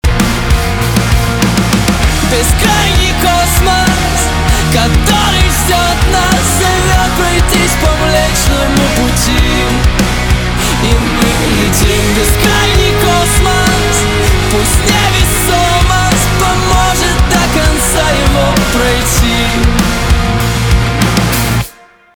русский рэп , гитара , барабаны , чувственные